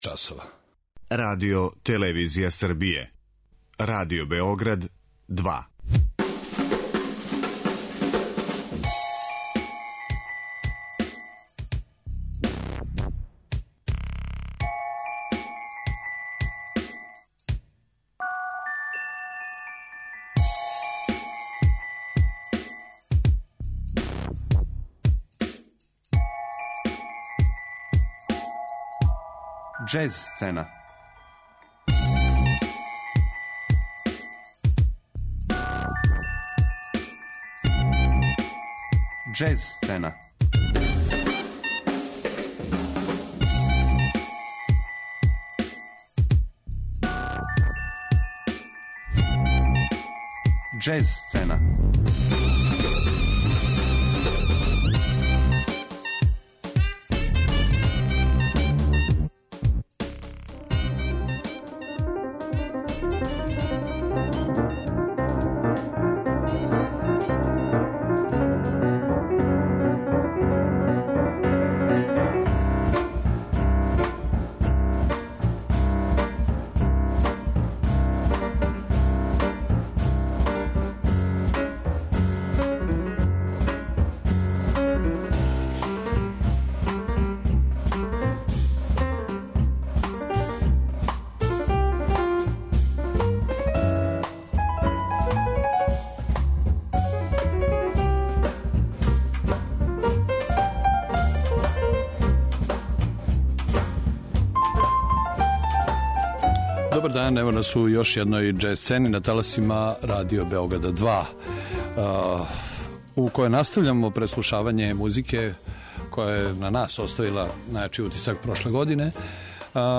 У другој емисији у 2016. години, завршавамо преслушавање најупечатљивијих америчких издања из 2015. године и дајемо преглед најзанимљивијих албума са европске џез сцене.